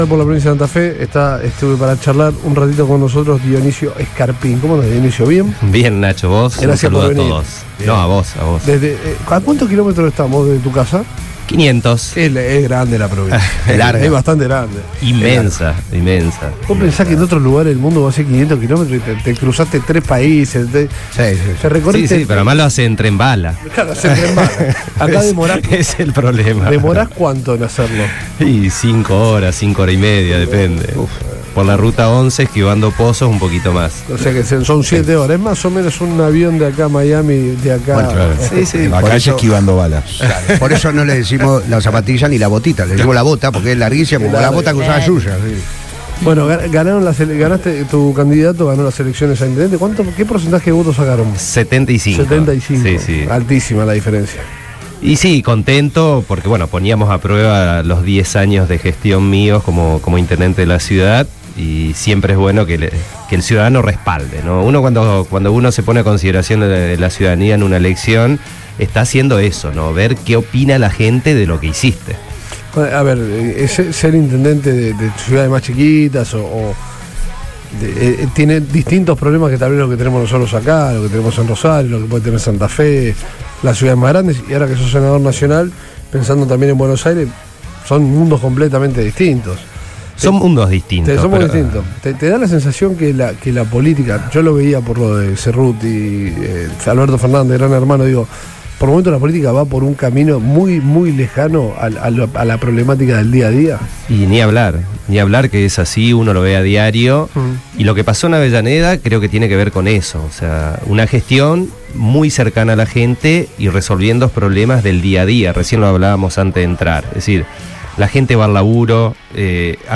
en Todo Pasa por Radio Boing donde habló sobre la situación del país y del resultado de las elecciones en Avellaneda